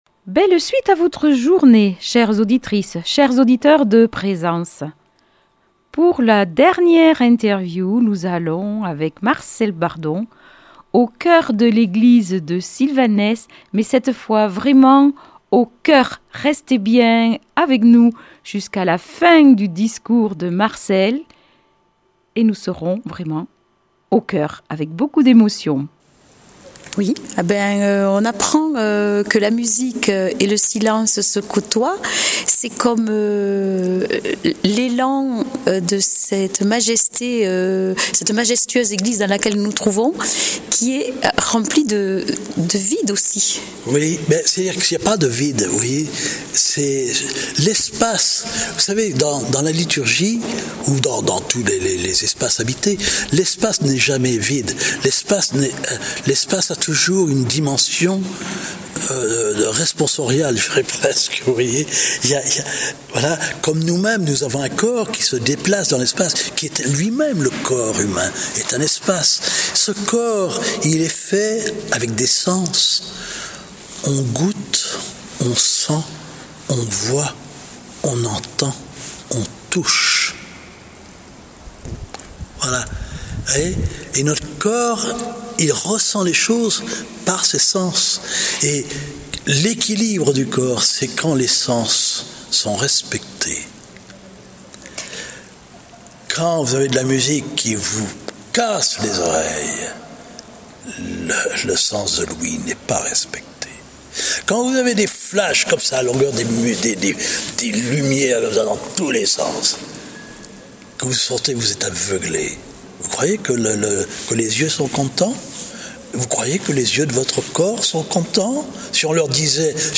Polyphonies de Sylvanès